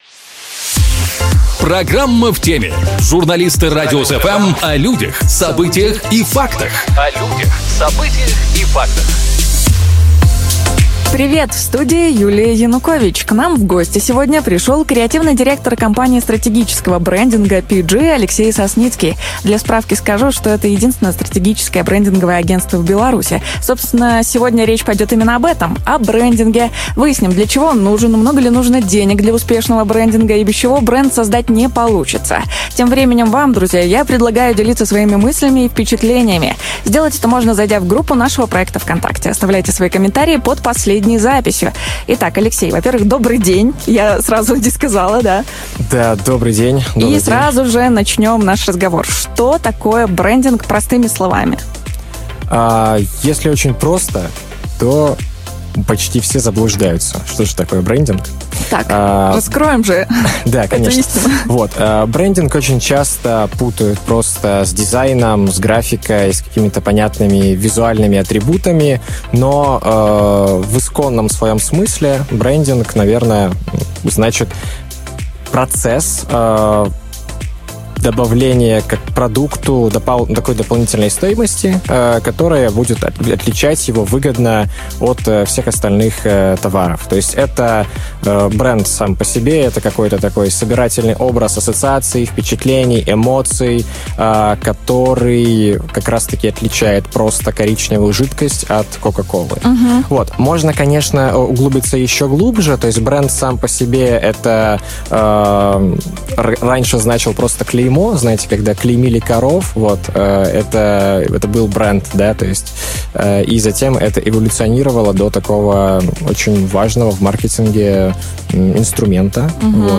В студии "Радиус FМ"